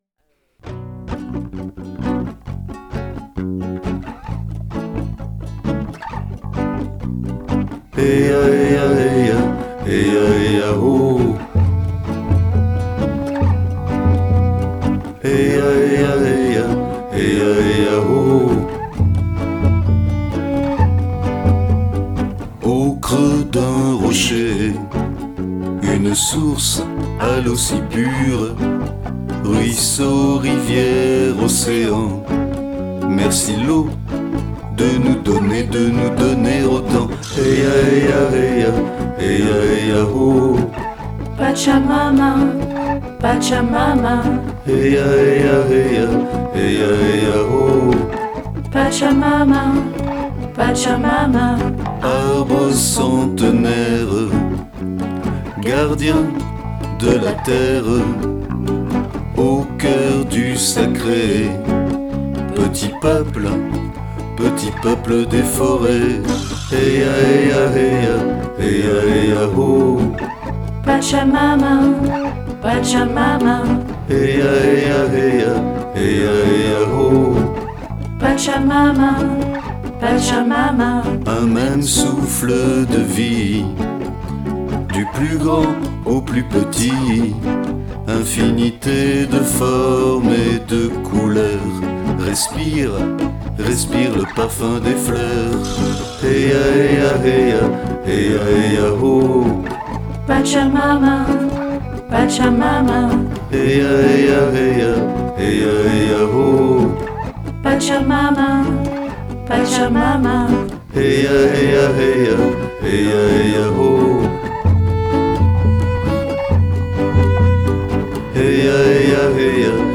guitar, bass, cajon, vocals
violin
flute